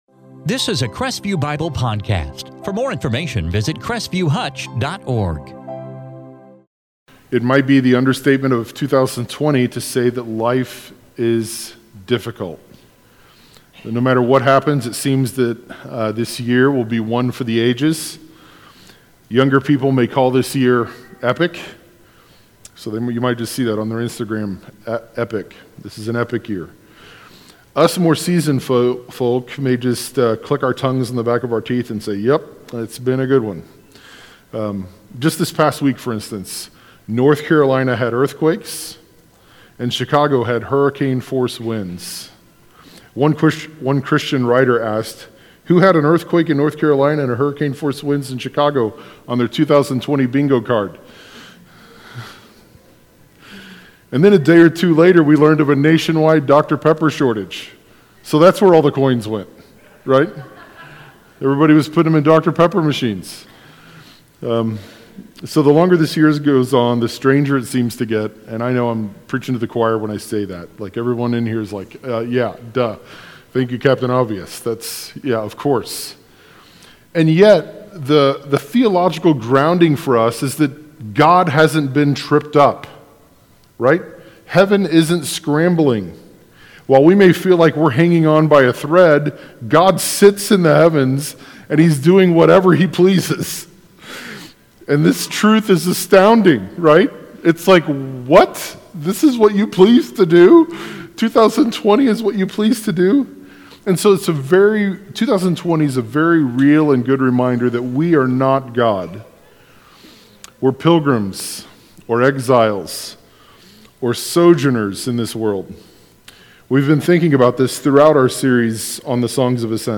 2020 Psalms of Ascent Psalm Transcript In this sermon from Psalm 132